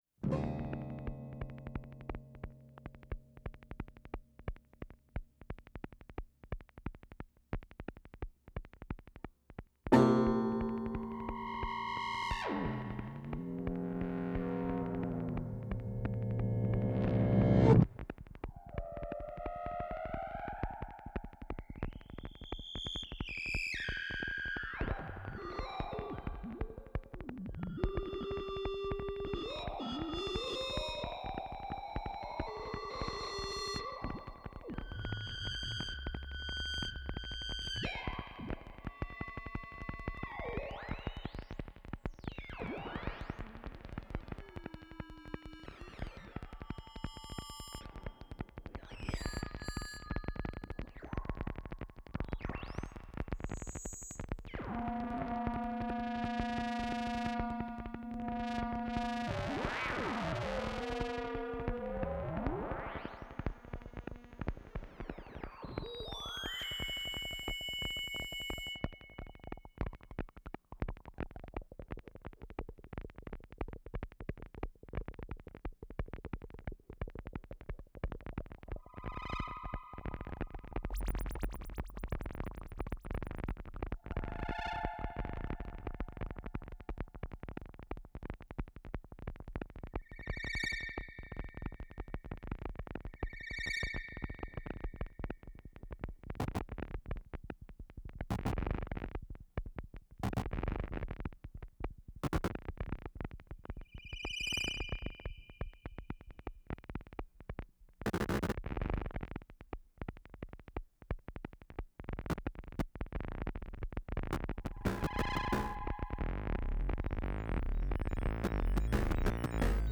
Electronic Music
analog-study-no-1.wav